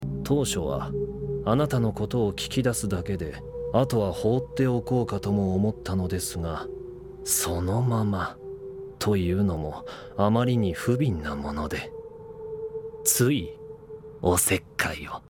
From the voice acting, I might guess that 2) fits the context best, but that's a just a guess.